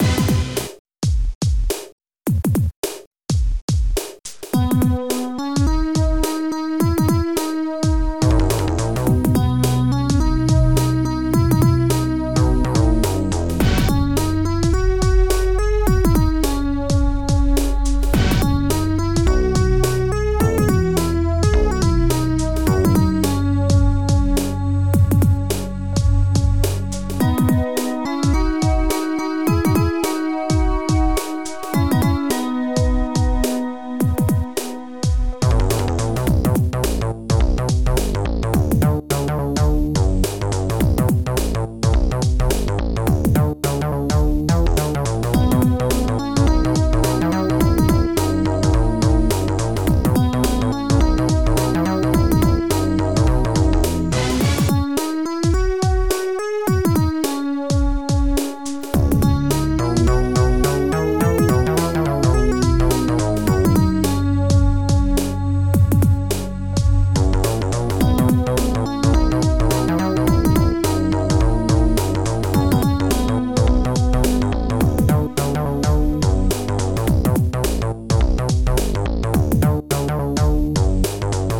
Epic Megagames MASI Module  |  1995-09-26  |  182KB  |  2 channels  |  44,100 sample rate  |  1 minute, 21 seconds
Bass Drum 4 (N)
Another Snare Drum
Electric Piano
Cold Orchestra Hit
Orgsolo